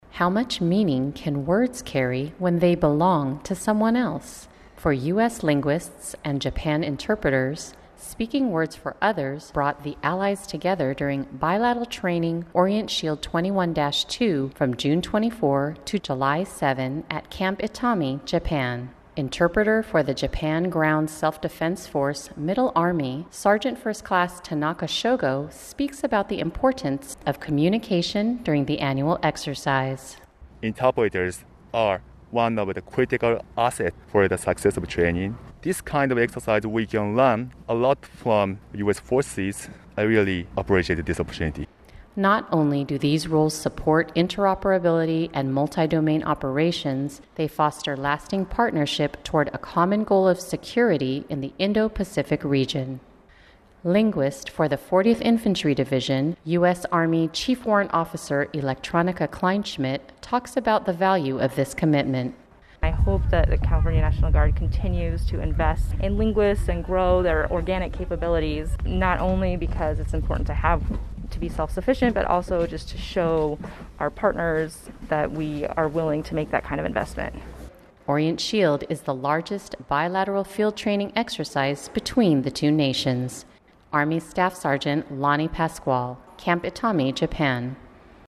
Japan Ground Self-Defense Force interpreters and U.S. Army 40th Infantry Division linguists support bilateral training exercise Orient Shield 21-2 from June 24 to July 7, 2021 at Camp Itami, Japan.